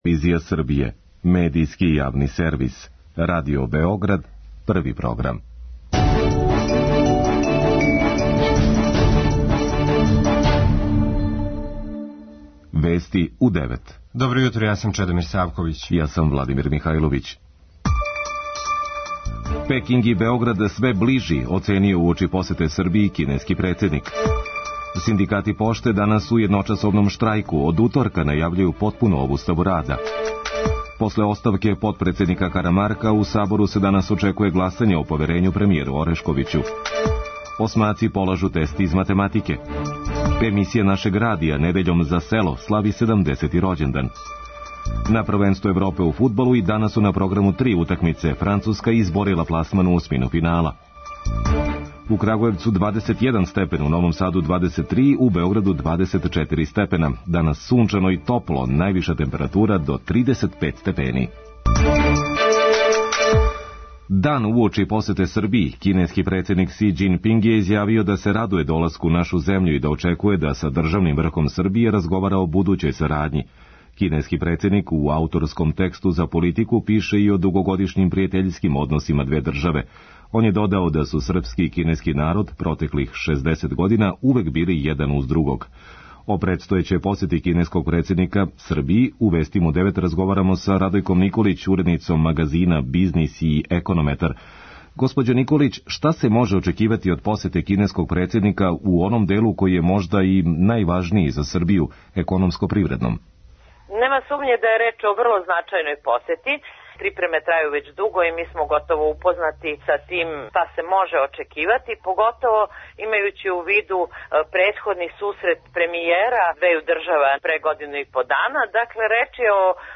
преузми : 4.00 MB Вести у 9 Autor: разни аутори Преглед најважнијиx информација из земље из света.